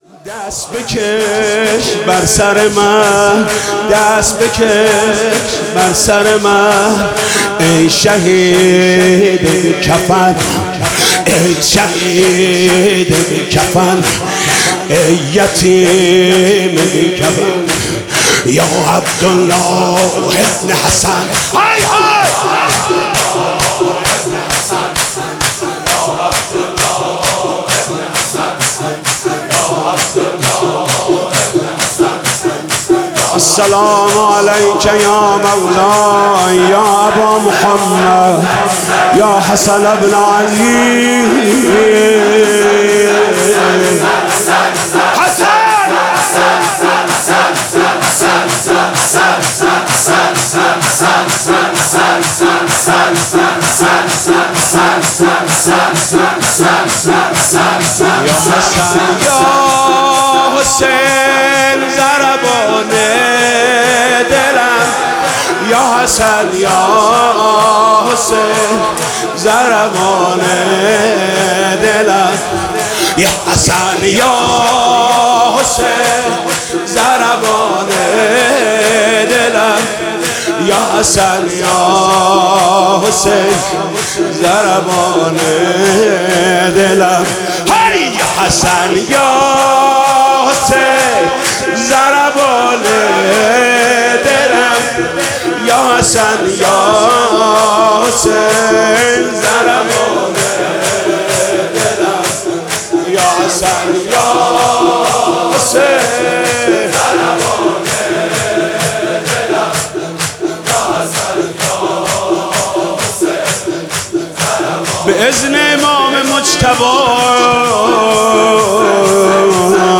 محرم96 - شور - دست بکش بر سر من